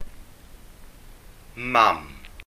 [mam] 'mother' See Welsh phonology